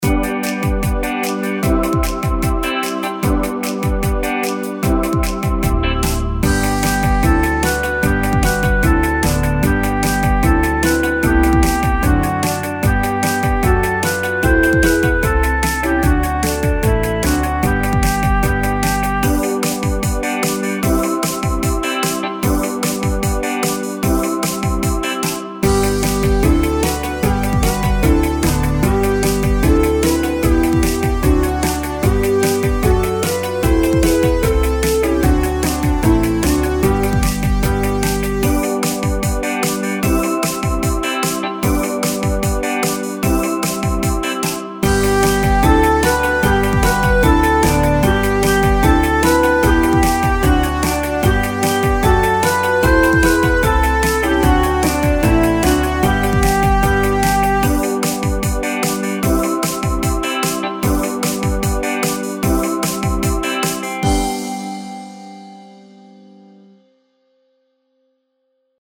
Nursery rhyme, England (U.K), Europe
karaoke
Kids Karaoke Song (Instrumental) YouTube License